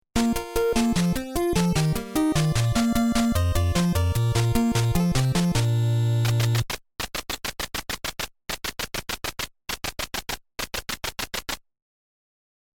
No Mouthing Off to Ma: Missing Chattering Audio